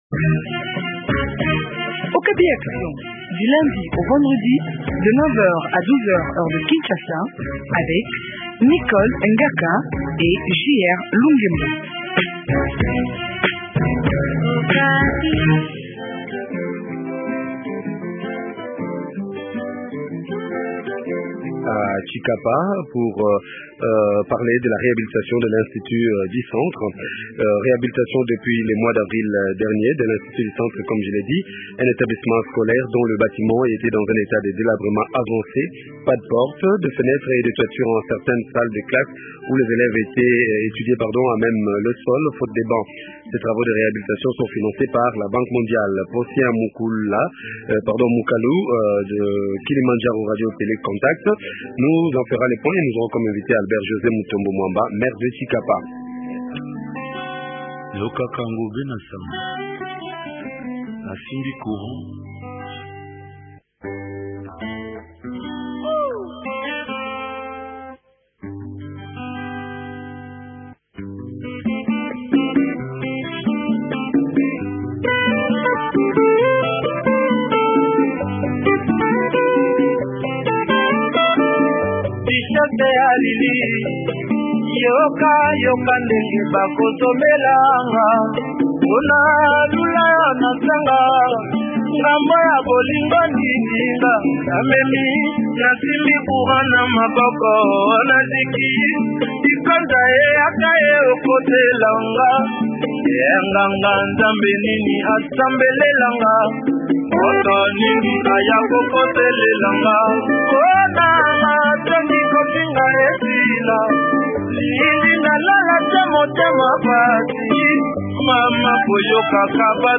Albert José Mutombo Mwamba, maire de Tshikapa